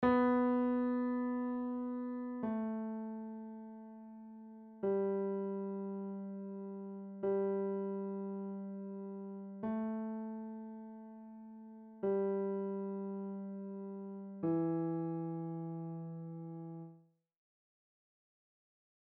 Look at the letters and find the notes on your piano; play the words
Piano Notes